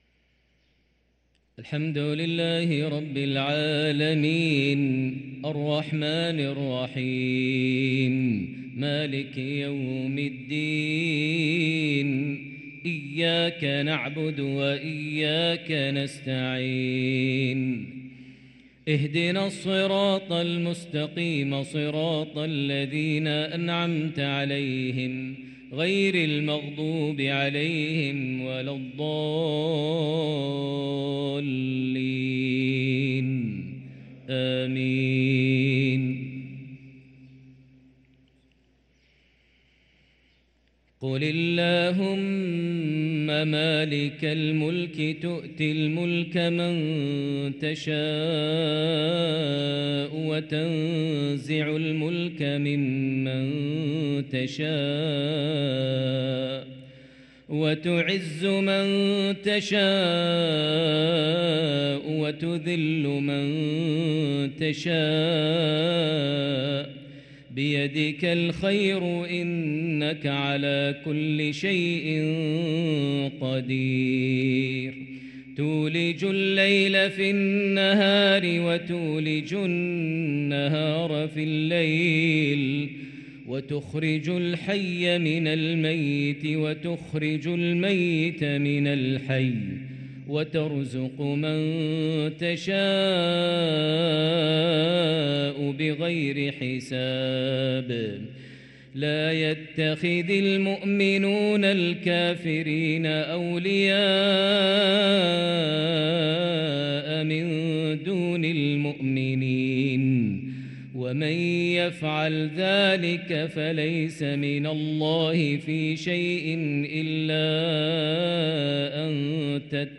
صلاة المغرب للقارئ ماهر المعيقلي 9 رجب 1444 هـ
تِلَاوَات الْحَرَمَيْن .